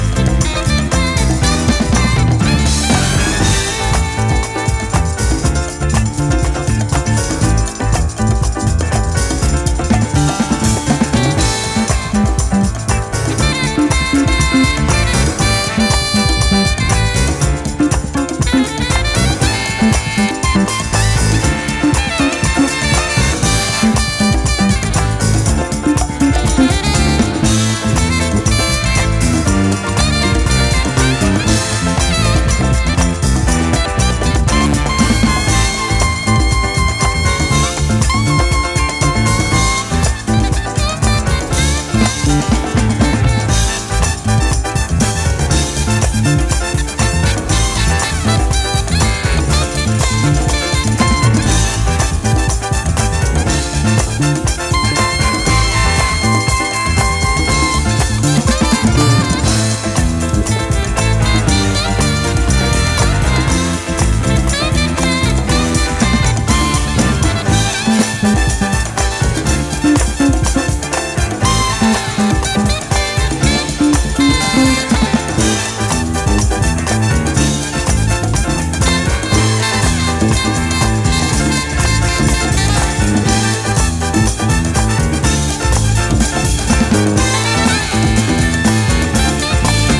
a treasure trove of garage classics and dance classics!